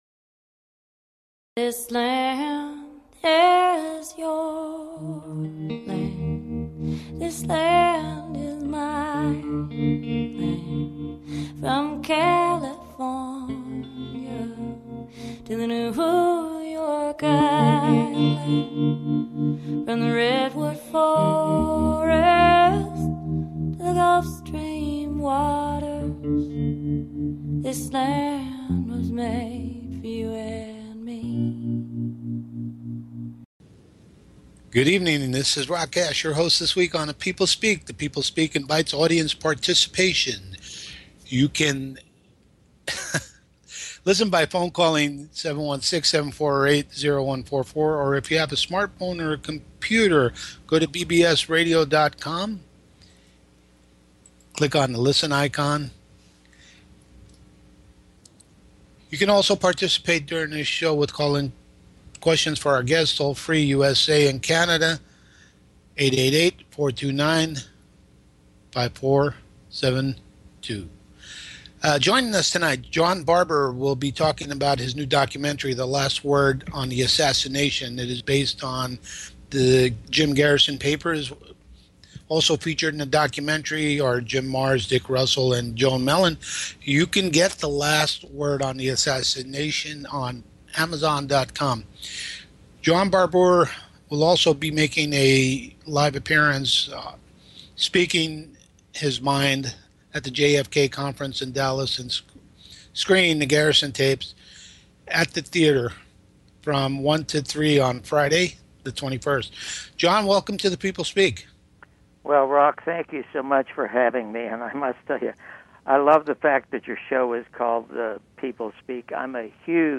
Talk Show Episode, Audio Podcast, LAST WORD ON JFK ASSASSINATION and John Barbour On JFK Conspiracy on , show guests , about John Barbour,The Last Word on the Assassination of JFK,Jim Garrison,Clay Shaw,JFK Conspiracy, categorized as History,News,Politics & Government,Theory & Conspiracy
Guest, John Barbour